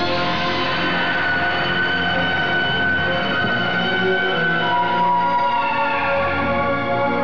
Godzilla's Death Cry from G 1985